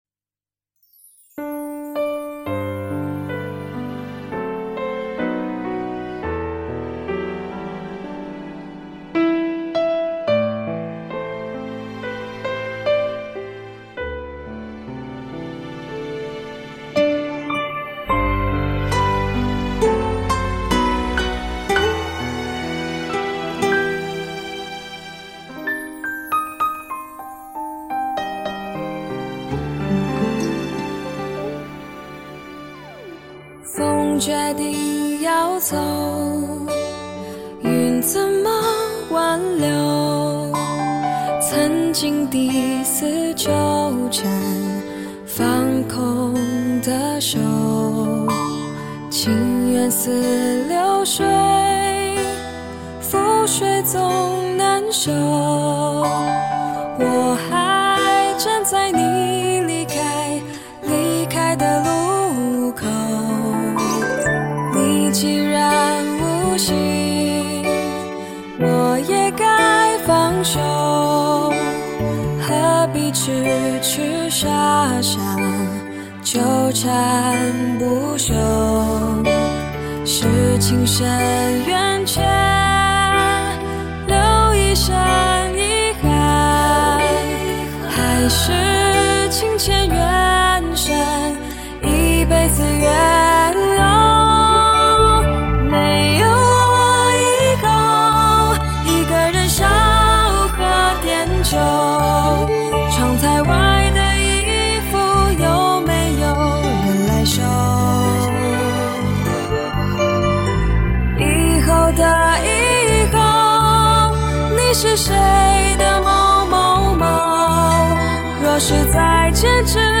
华语